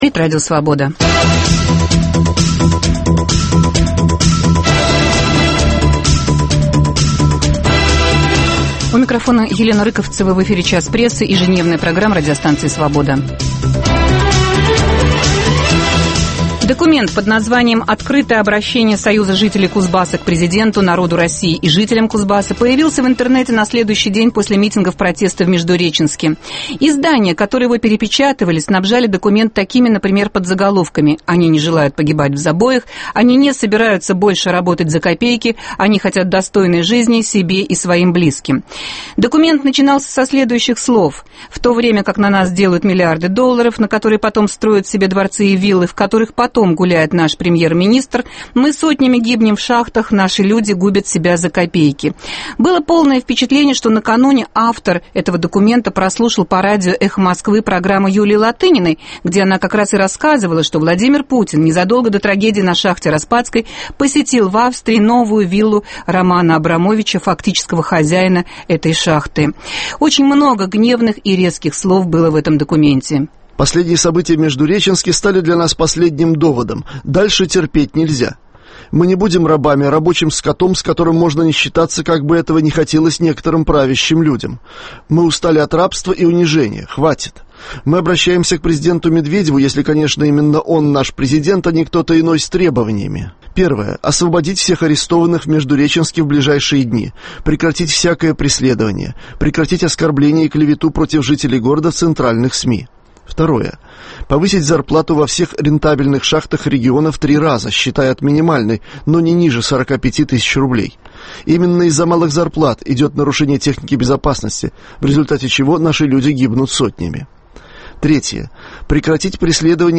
(по телефону)